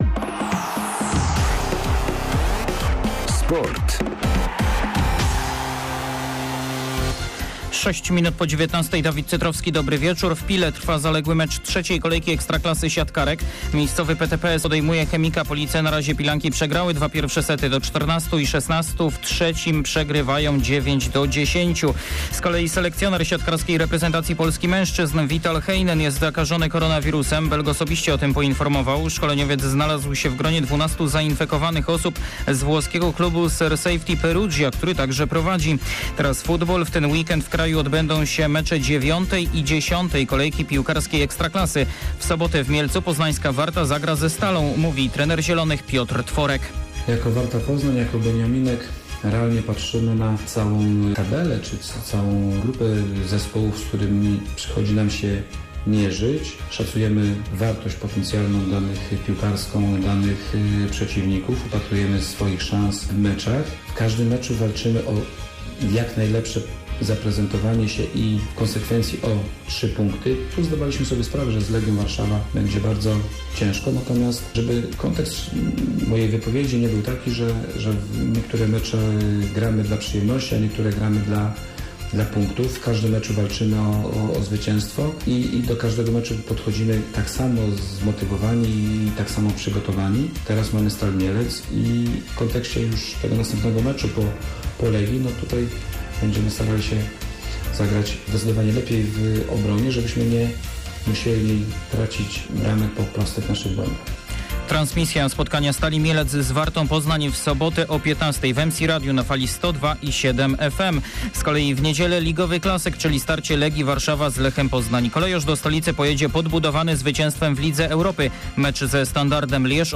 06.11.SERWIS SPORTOWY GODZ. 19:05